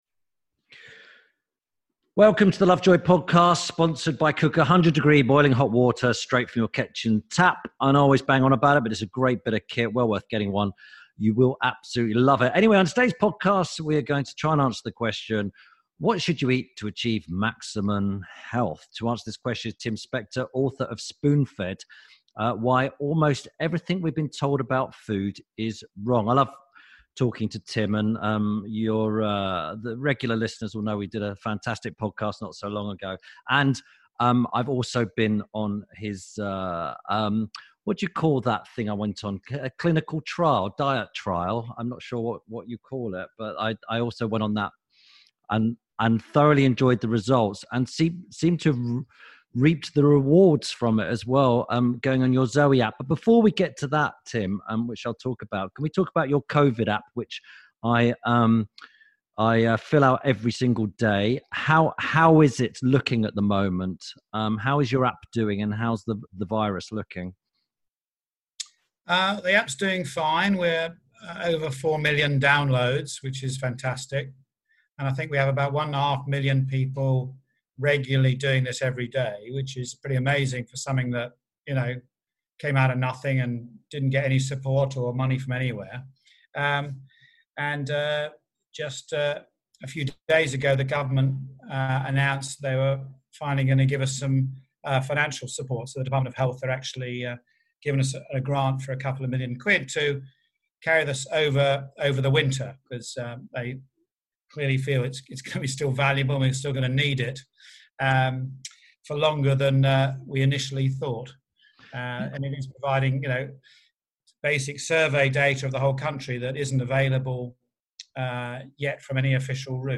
Tim Lovejoy speaks to Professor Tim Spector about food myths and what we should really be eating.